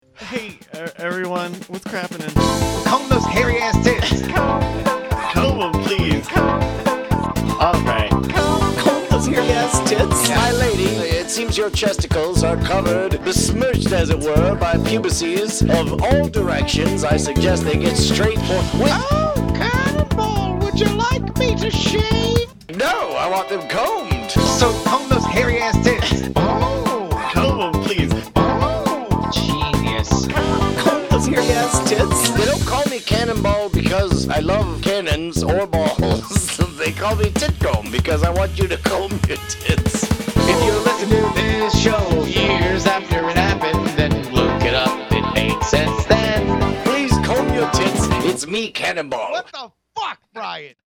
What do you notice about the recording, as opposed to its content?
This song (made from last week’s episode) is a fairly accurate representation of our show.